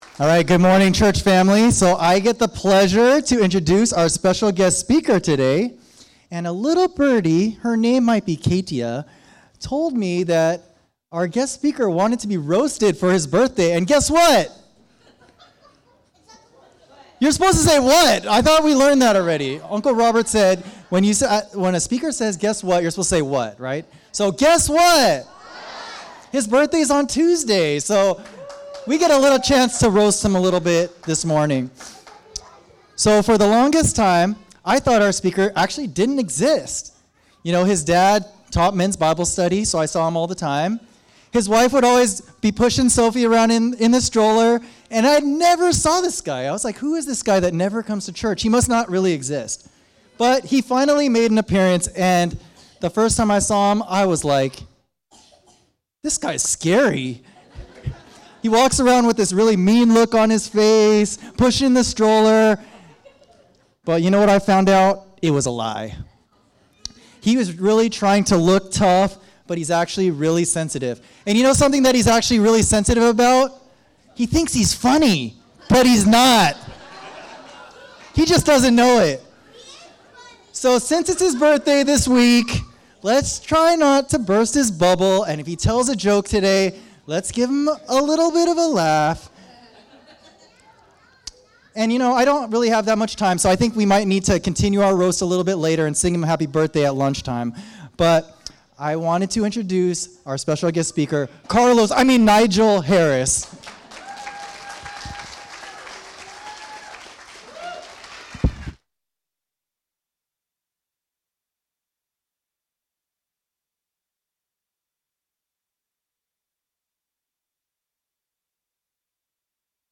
Sermons | Catalyst Christian Community
Catalyst Sunday Service 11.3.2024